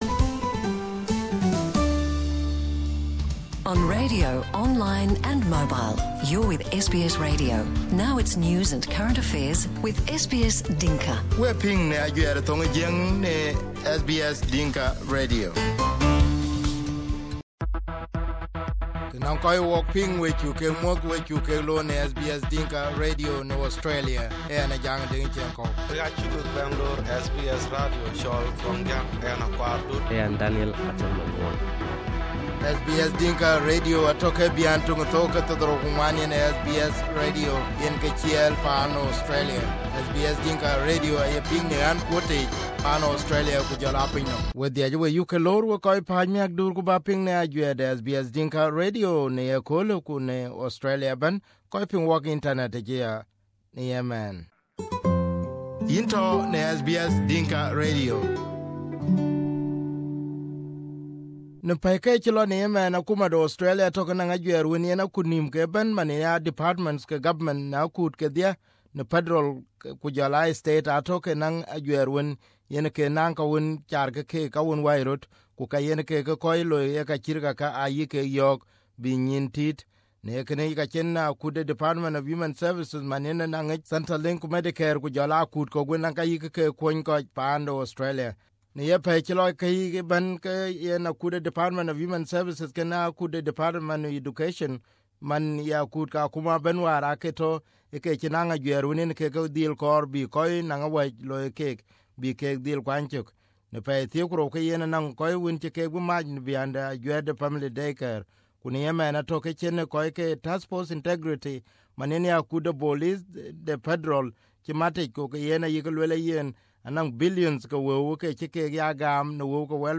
It is reported that fraudulent behaviour are seen on those claming Newstart Allowance, Parent and disability benefits. Here is the report